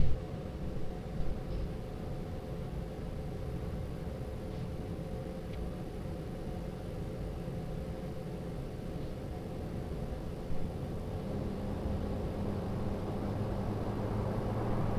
Характер шума, как таковой
В результате прореживания  длительность звукового фрагмента сократилась до 15 секунд, что соответствует монотонному повышению мощности нагрузки от минимальных значений до 100%.
Работа вентилятора начинает быть заметной лишь на полной мощности, а до того практически исчезает на уровне фонового шума.
Corsair_HX750i_fan.mp3